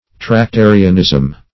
Tractarianism \Trac*ta"ri*an*ism\, n. (Ch. of England)